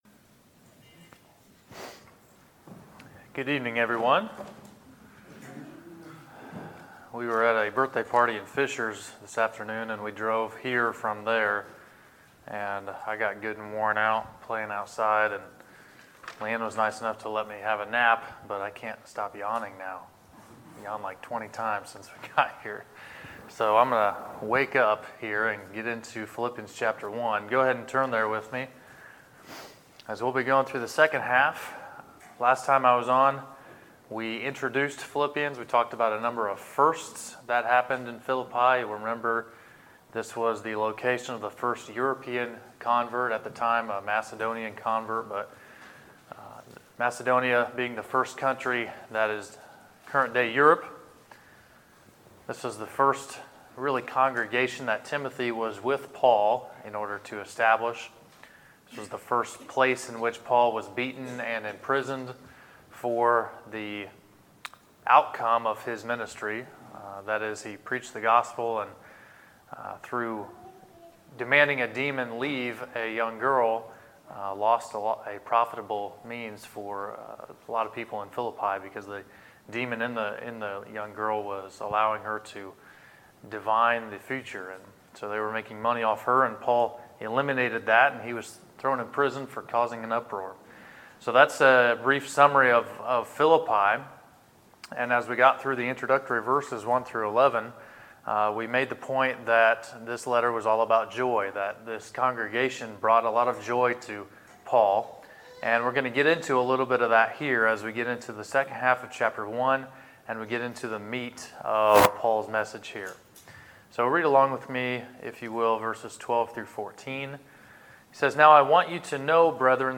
Sermons, October 14, 2018